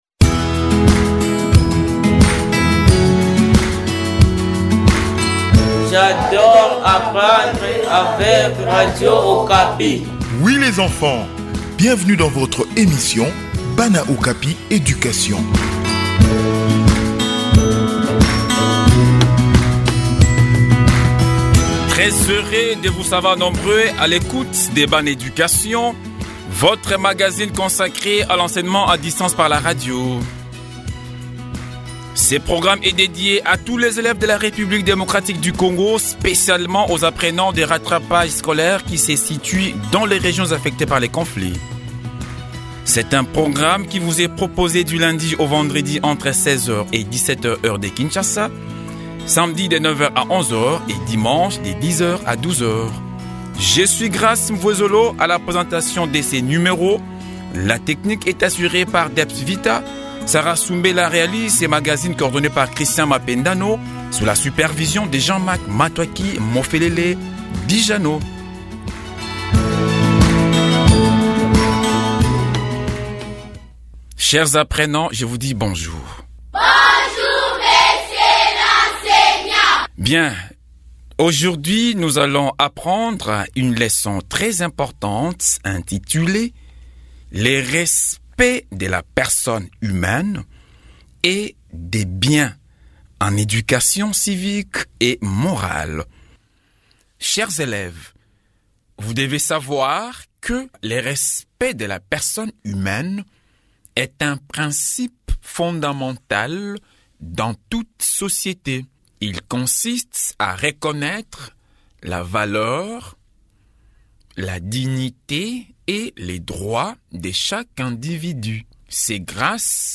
Éduquer au respect, c’est cultiver l’humanité et prévenir les conflits. Réécoutez cette leçon pour approfondir cette valeur essentielle.